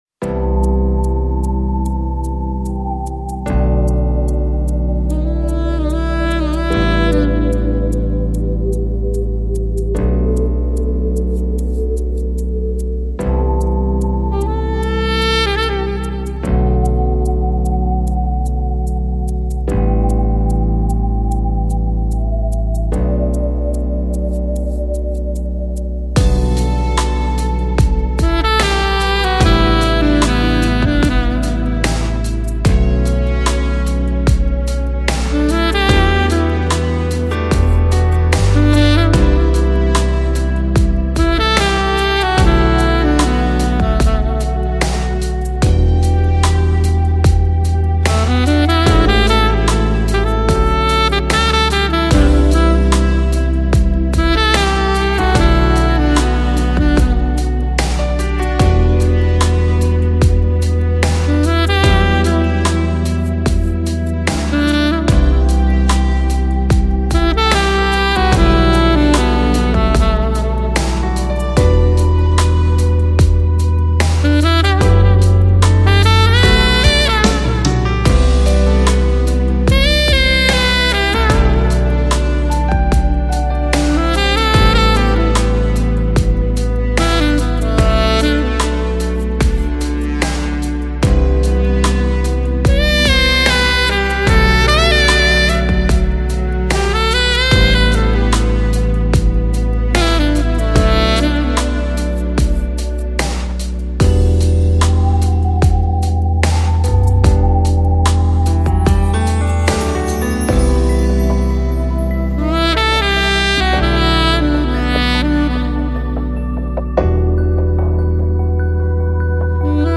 Жанр: Electronic, Lounge, Chill Out, Downtempo, Ambient